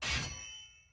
King Bradley Unsheathes His Blade.wav